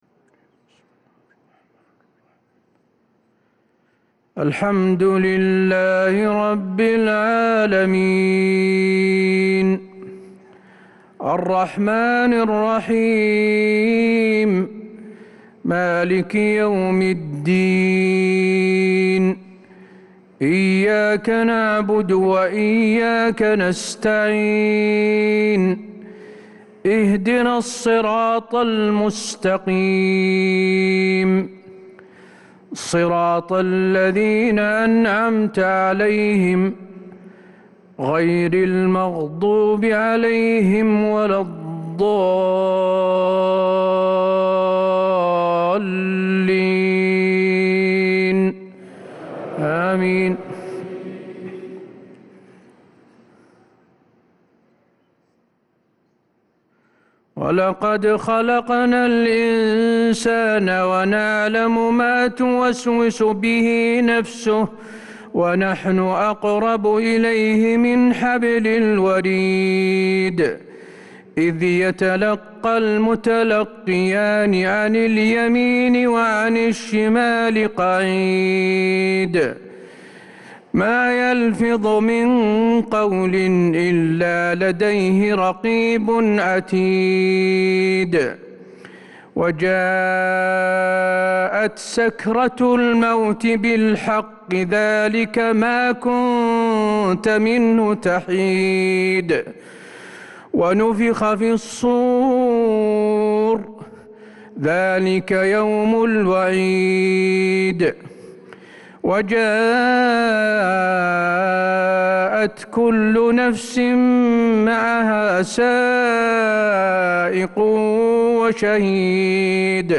صلاة العشاء للقارئ حسين آل الشيخ 23 ذو الحجة 1443 هـ
تِلَاوَات الْحَرَمَيْن .